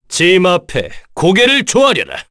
Kain-Vox_Victory_kr_c.wav